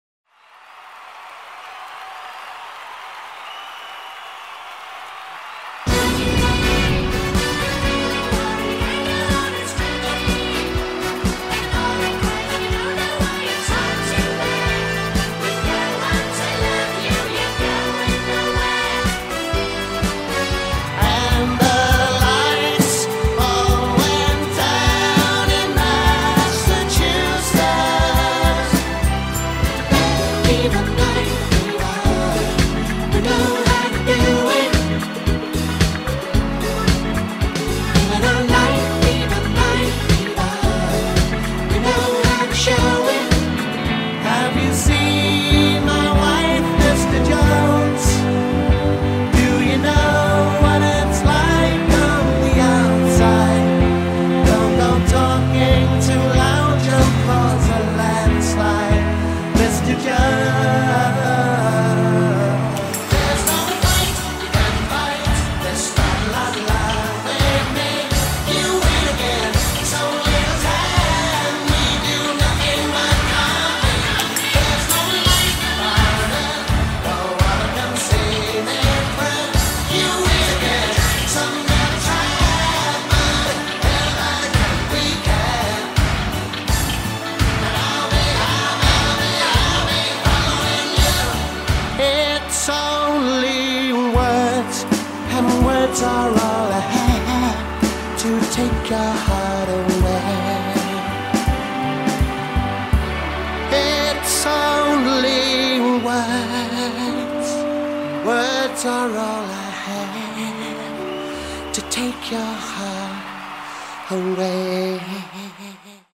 6 piece
complete with falsettos